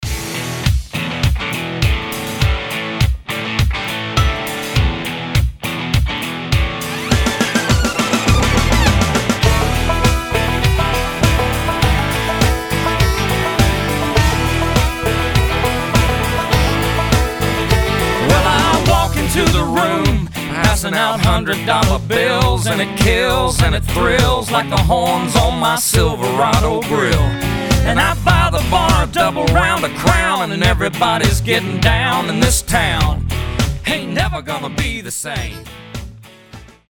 • Качество: 320, Stereo
мужской вокал
электрогитара
банджо
country